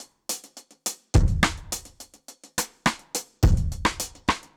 Index of /musicradar/dub-drums-samples/105bpm
Db_DrumsB_Dry_105-01.wav